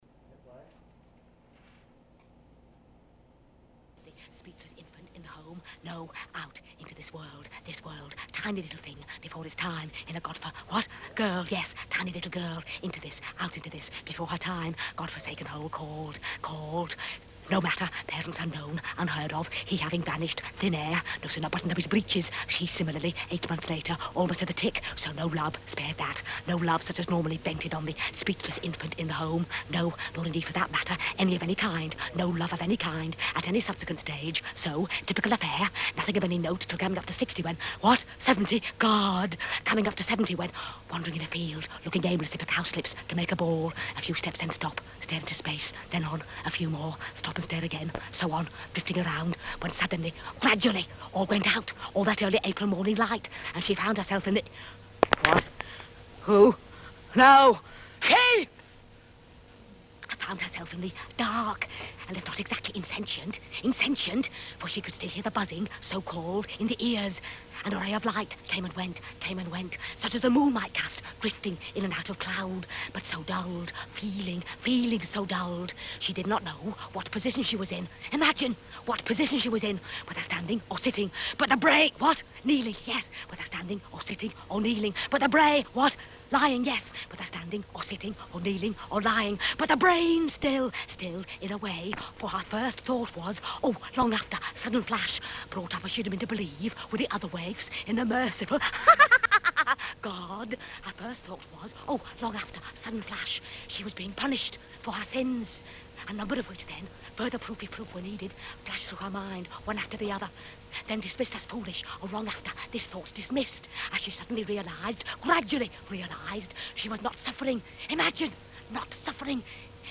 (Vocalise: Directing) This broadcast originally aired on Montez Press Radio as Tongue and Cheek- Ep4: Furrows - Friday, August 17th, 2018, 10 a.m. -11 a.m. Play In New Tab (audio/mpeg) Download (audio/mpeg)